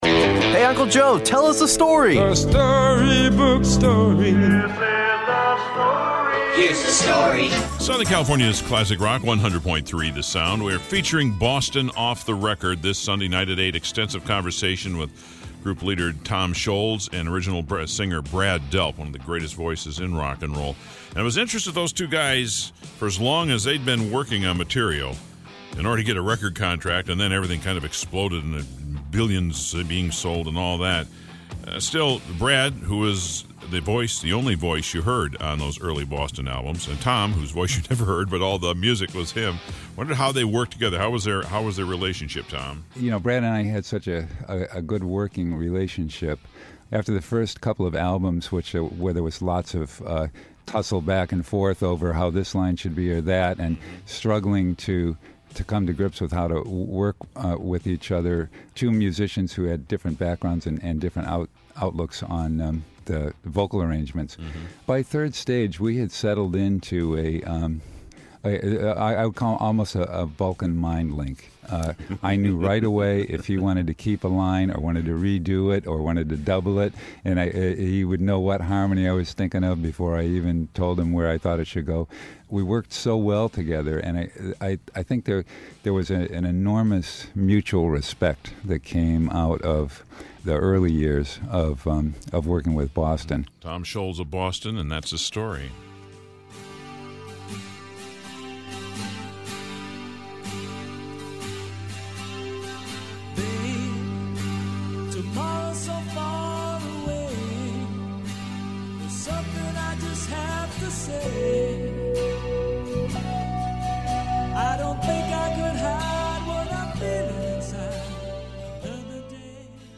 Boston’s Tom Scholz describes his songwriting relationship with original singer Brad Delp.